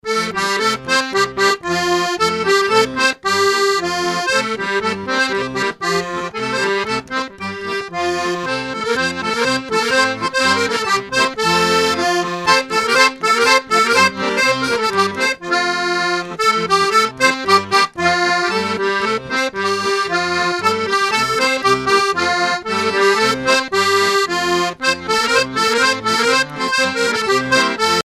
danse : galop (danse)
Coueff's et Chapias Groupe folklorique
répertoire du groupe Coueff's et Chapias en spectacle
Pièce musicale inédite